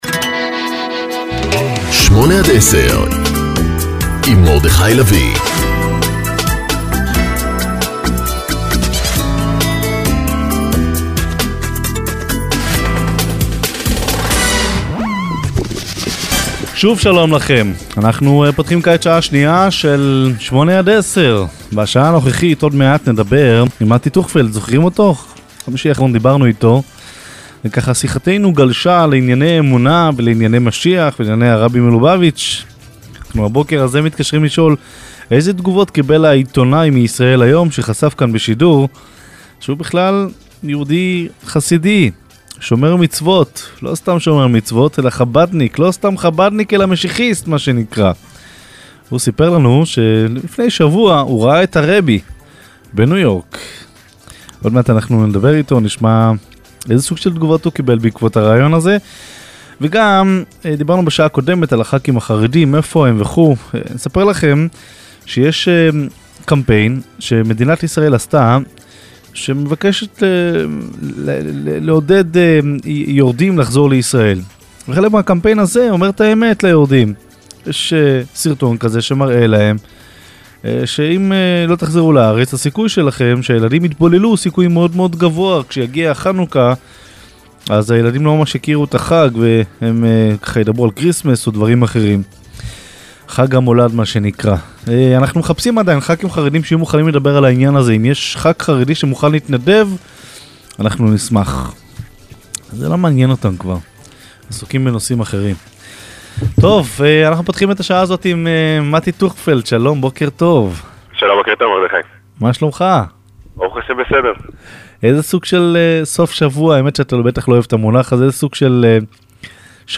בראיון